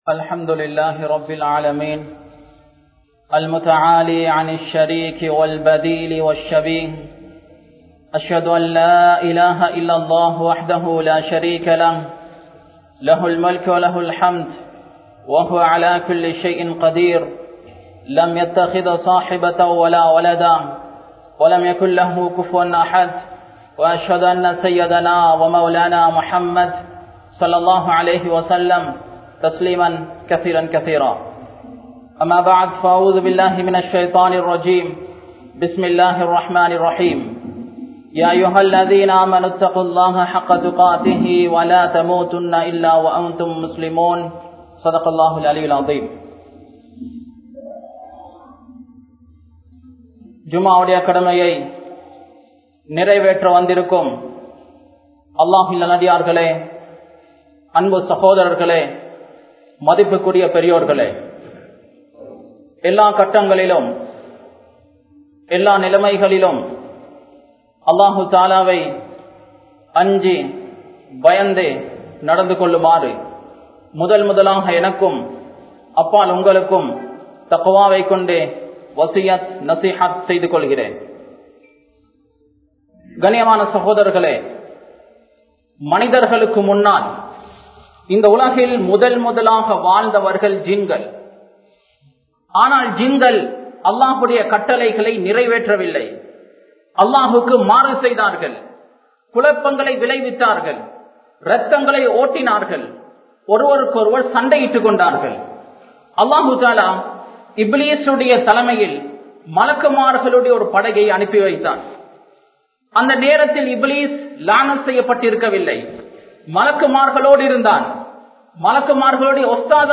Iruthi Naalai Noakki (இறுதி நாளை நோக்கி) | Audio Bayans | All Ceylon Muslim Youth Community | Addalaichenai
Jamiul Azhar Jumua Masjidh